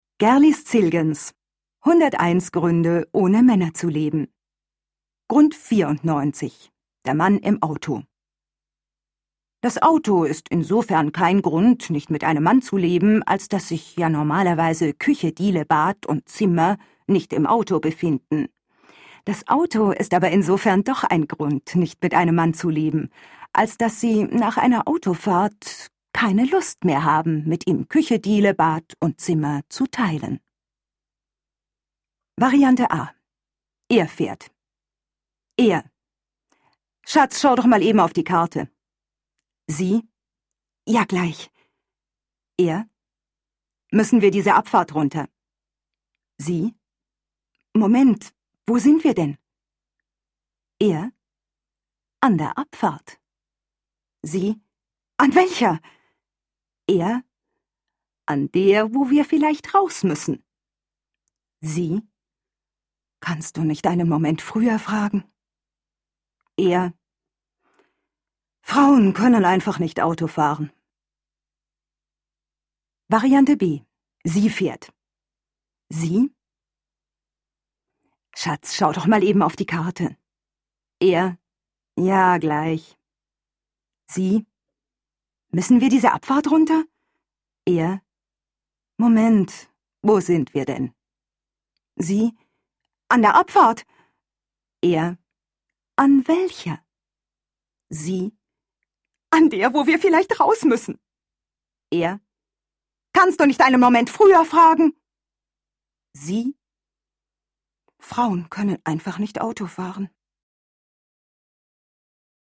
deutsche Sprecherin, Sängerin und Schauspielerin.
Sprechprobe: Industrie (Muttersprache):